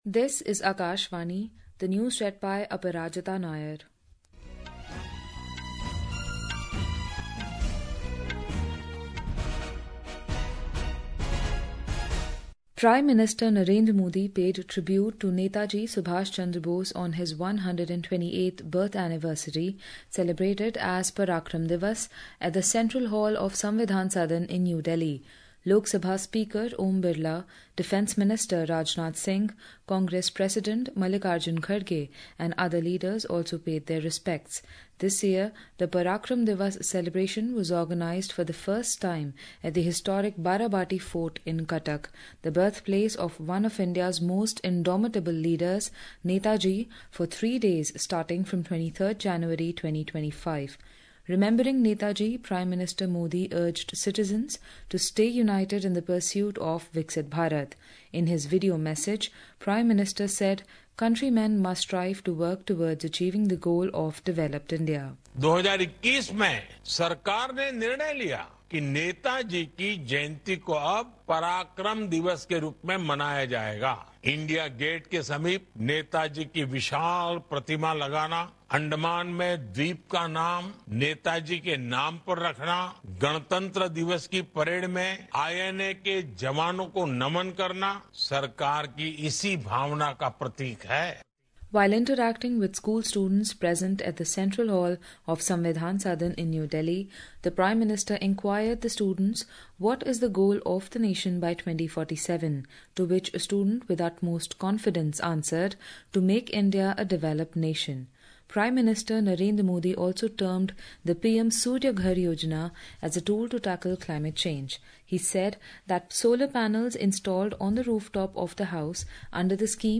قومی بلیٹنز
Hourly News